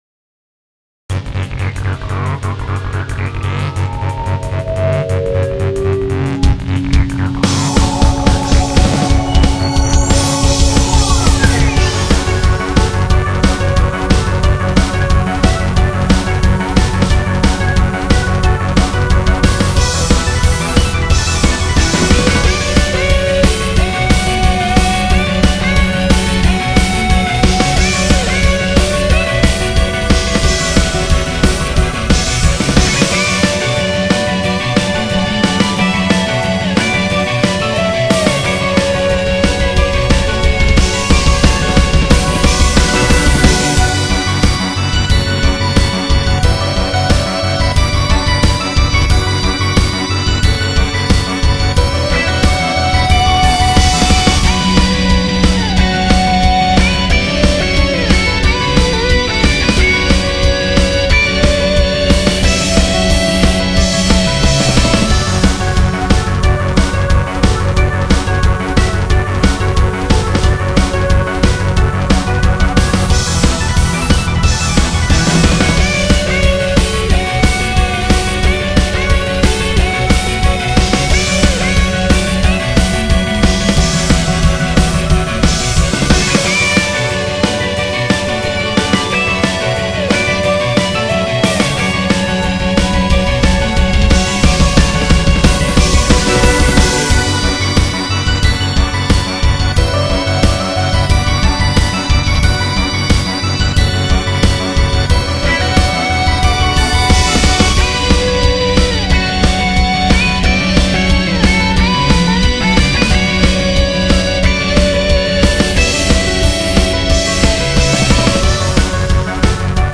レースゲームを妄想してロック風に。